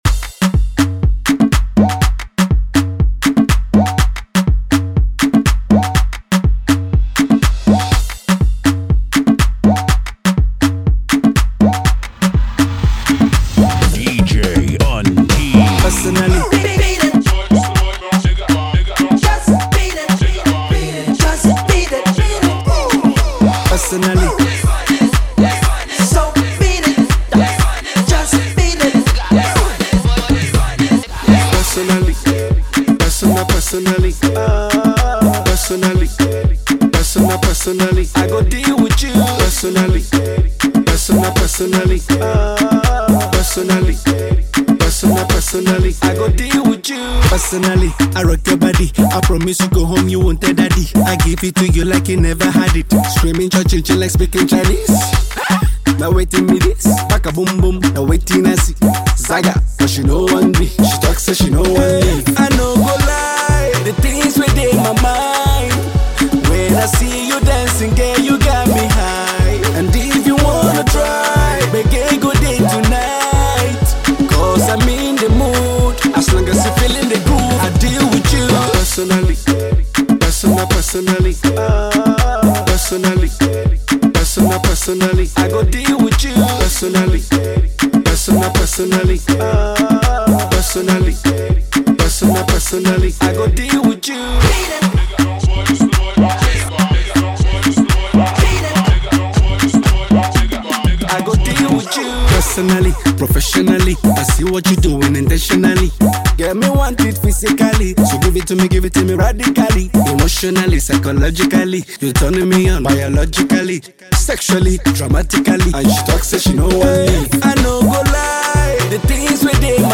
Let´s spice up the party with this hot partymix.